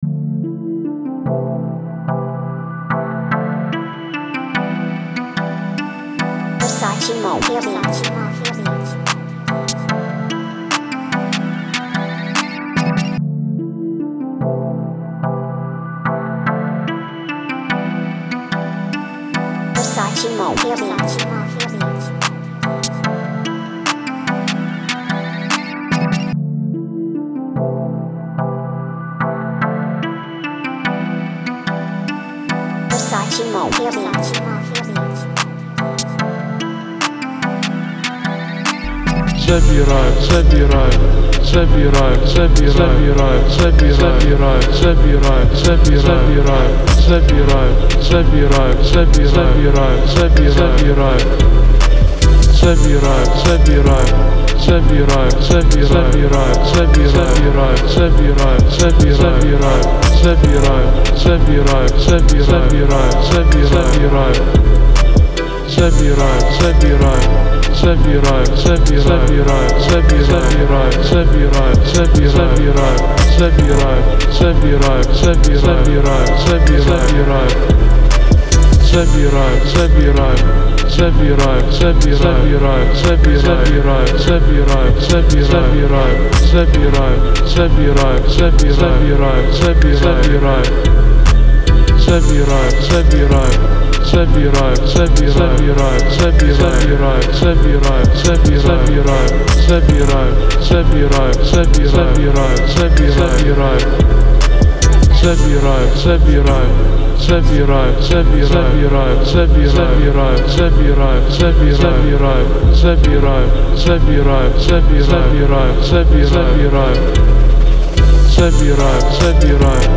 поп-рэп